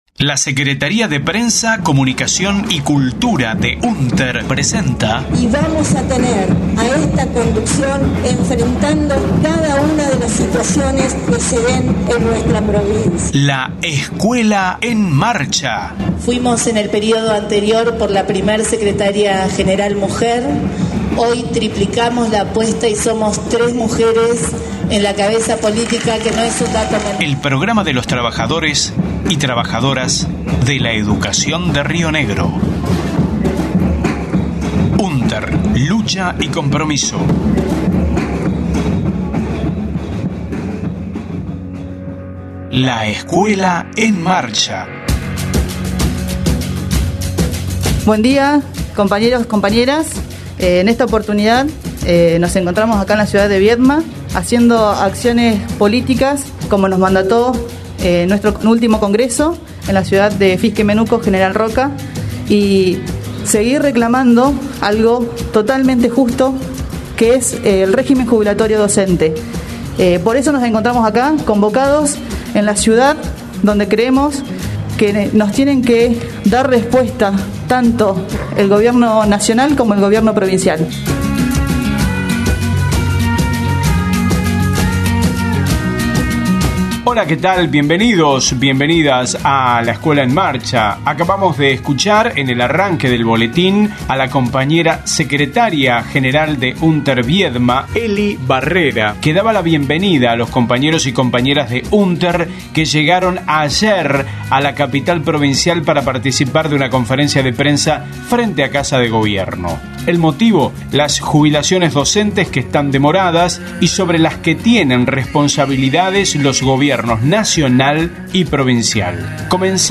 LEEM 24/09/21: Jubilaciones docentes demoradas por responsabilidad de los gobiernos provincial y nacional. Audio de conferencia de prensa, realizada en Viedma, frente a Casa de Gobierno el 24/09/21.